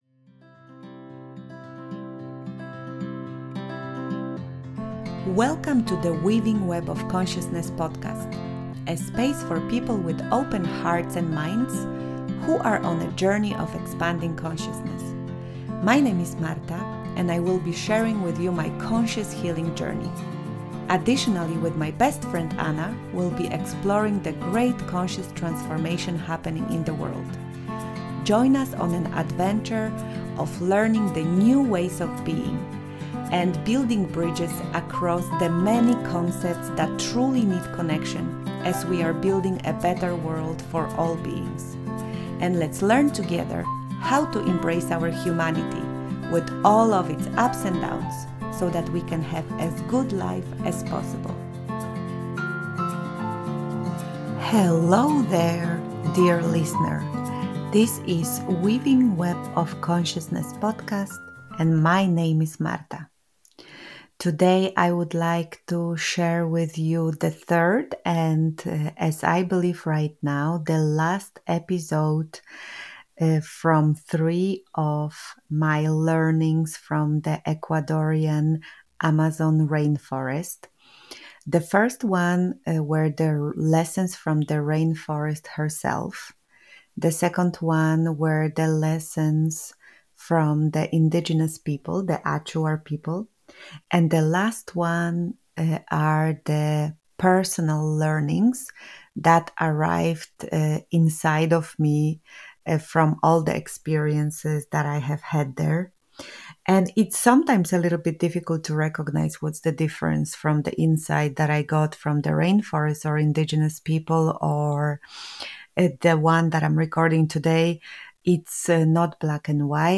Ep 11 Lessons From The Leaders & My Personal Insights From The Rainforest (Solo Show)